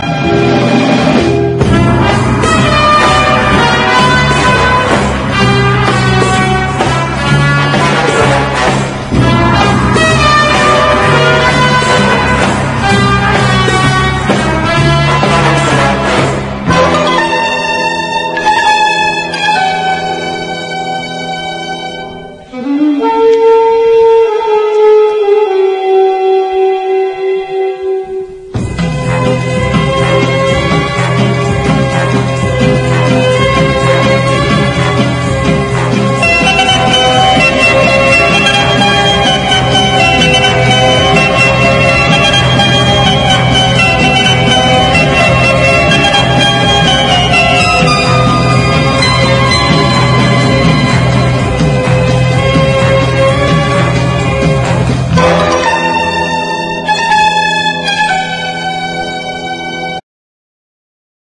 EASY LISTENING / OST / SOFT ROCK
50、60年代のTVテーマを集めたグレイト・コンピレーション！